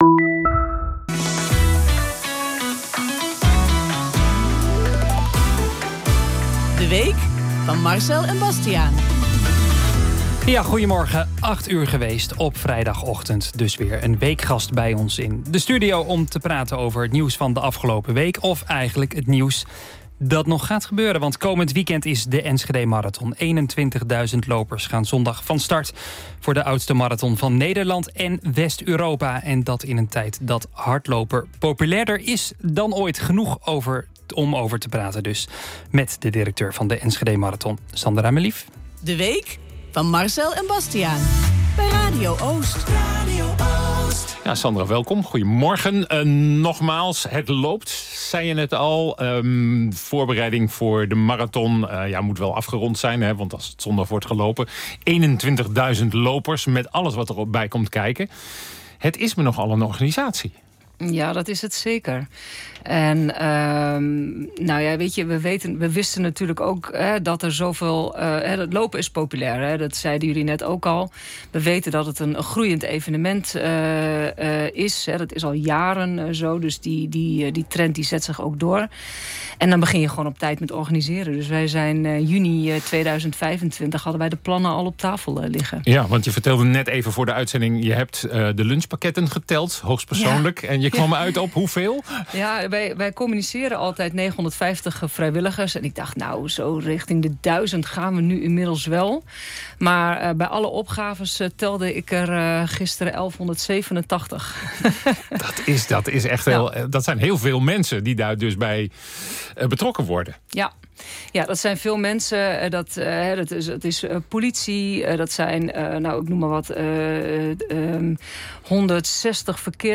Ook schuift er wekelijks een prominente Overijsselaar aan om het nieuws mee te bespreken. Met De week van Overijssel weet je alles over de week die geweest is en ben je klaar voor de week die komen gaat.